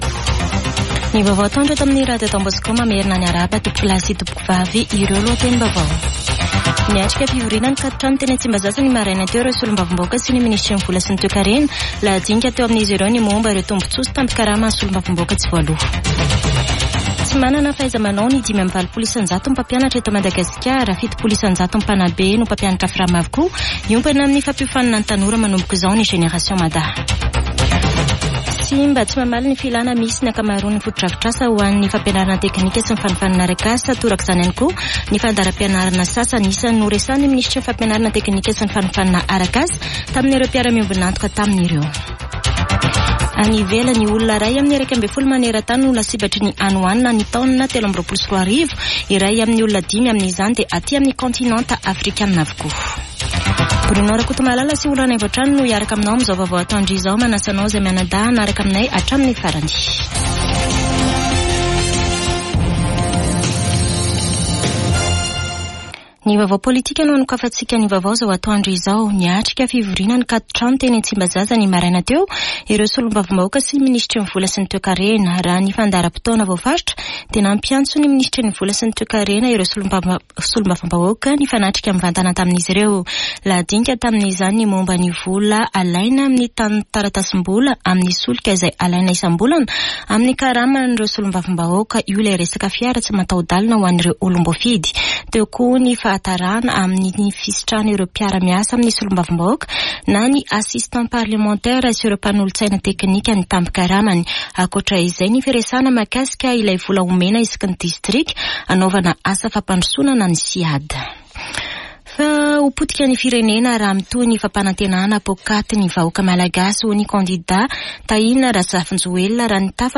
[Vaovao antoandro] Alakamisy 17 oktobra 2024